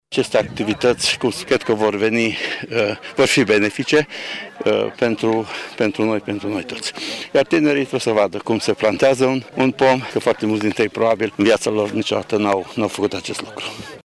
Inspectorul şcolar general Ştefan Someşan a spus că o astfel de acţiune îi învaţă pe tineri să fie responsabili: